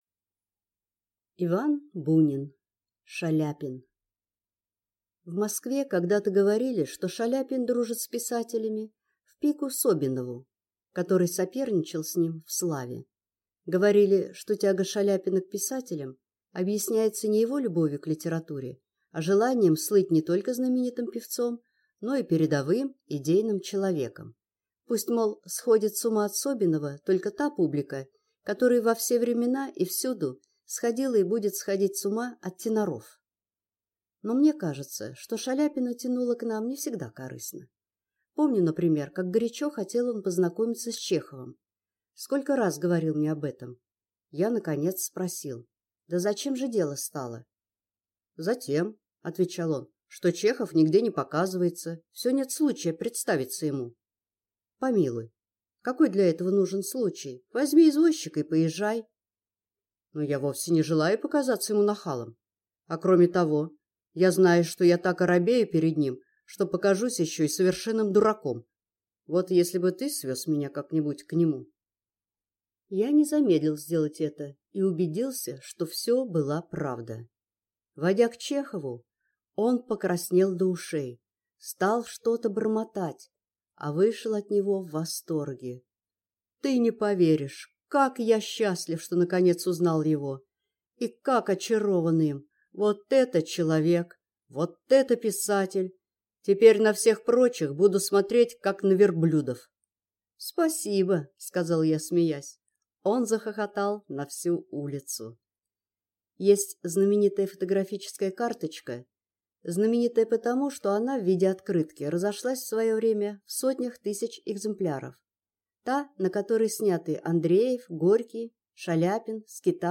Аудиокнига Шаляпин | Библиотека аудиокниг